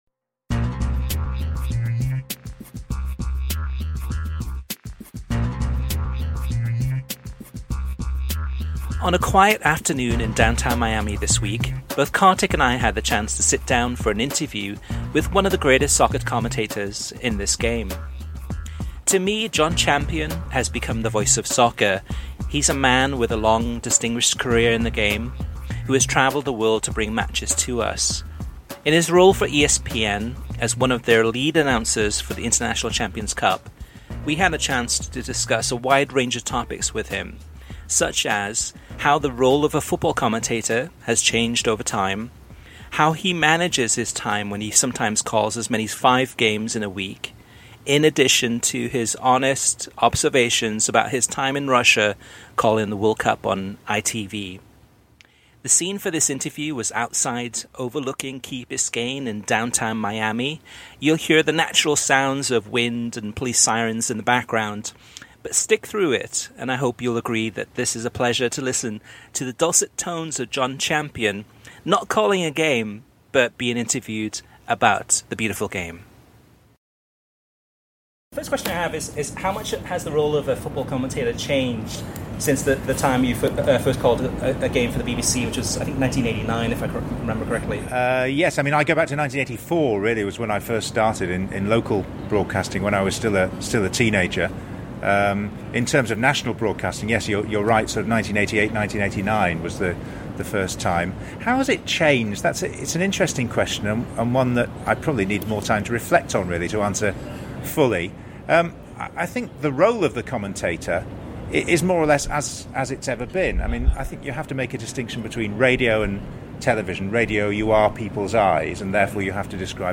Interview with Jon Champion, the voice of soccer